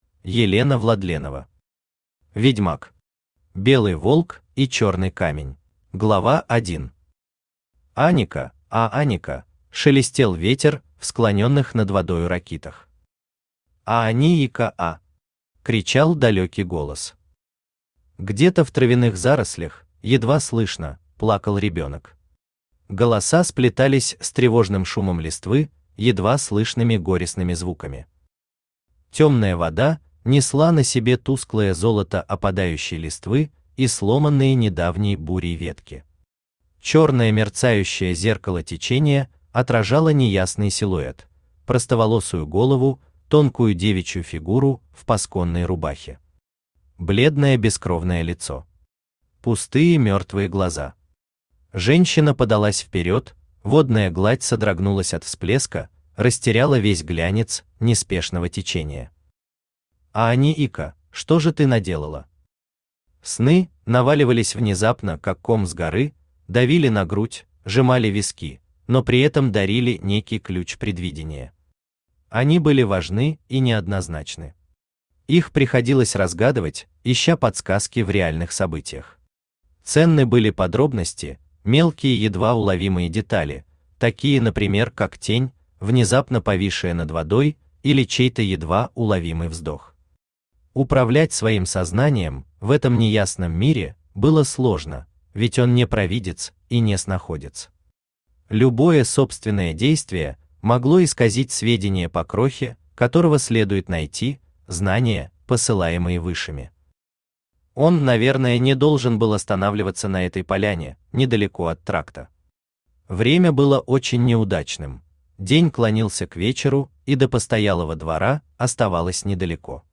Аудиокнига Ведьмак. Белый волк и чёрный камень | Библиотека аудиокниг
Aудиокнига Ведьмак. Белый волк и чёрный камень Автор Елена Владленова Читает аудиокнигу Авточтец ЛитРес.